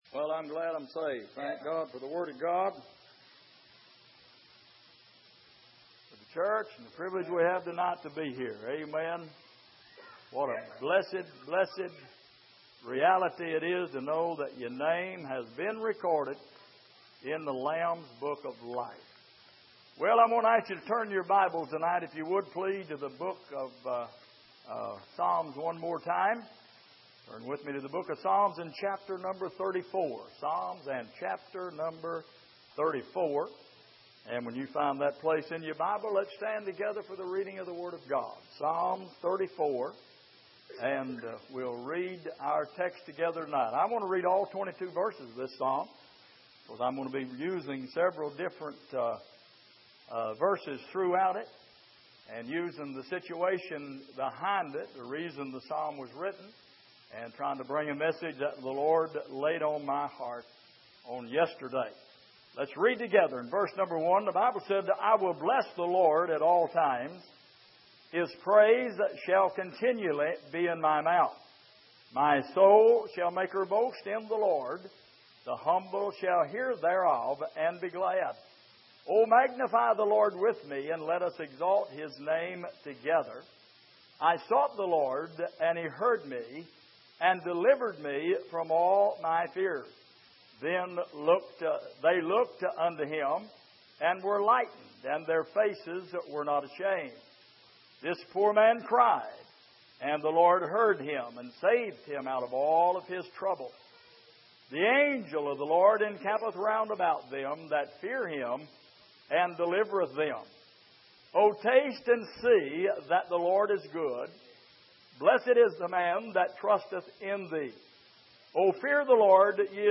Passage: Psalm 34:1-22 Service: Midweek